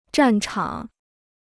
战场 (zhàn chǎng) Kostenloses Chinesisch-Wörterbuch